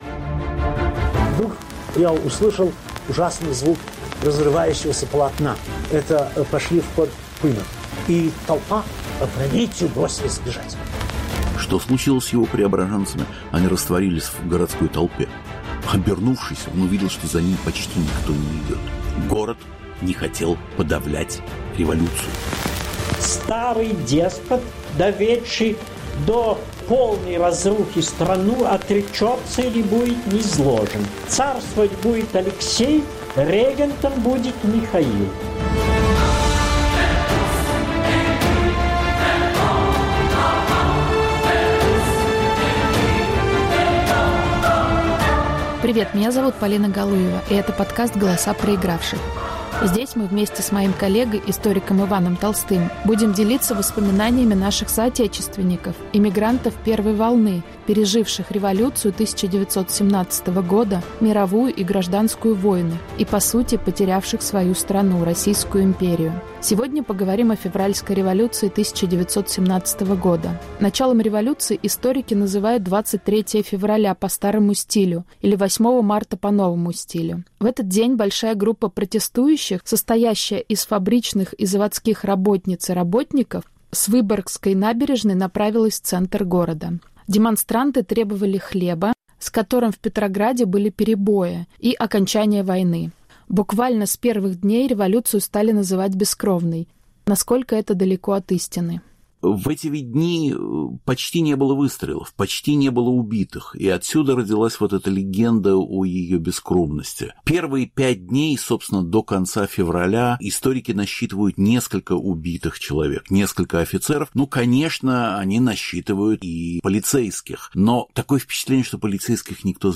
Рассказывают свидетели революционного Петрограда.